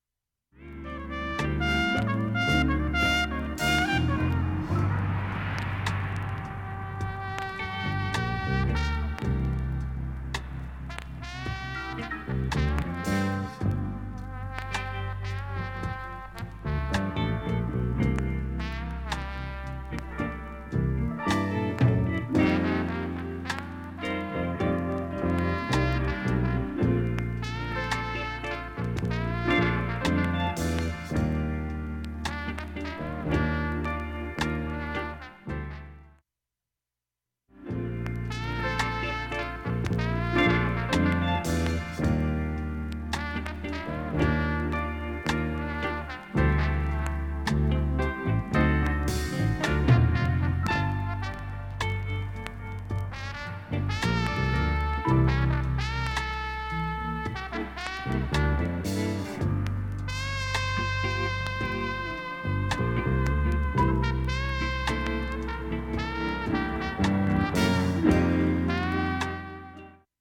瑕疵部分 B-2中盤に１３ミリと５ミリのスレで 180秒の間に周回プツ出ますがかすかです。
メンフィスで1973年に録音した